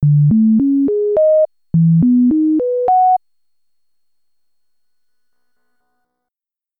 Here's some Echo slider examples to illustrate the difference in sound:
EXAMPLE 1: C700 default echo sliders values (50 and -50):
c700_echo_default_Rinvert.mp3